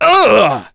Dakaris-Vox_Damage_04.wav